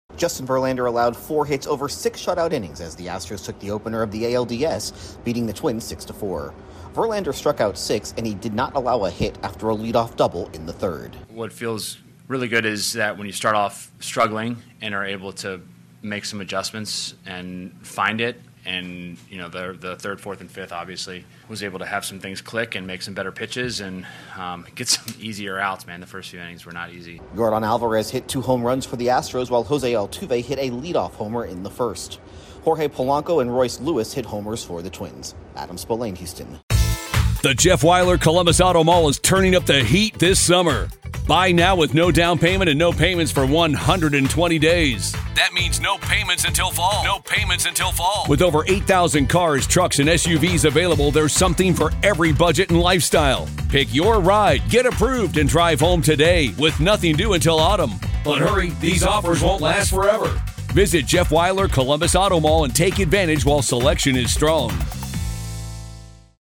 The Astros build a 5-0 lead before holding off the Twins. Correspondent